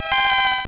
Cri de Lainergie dans Pokémon Or et Argent.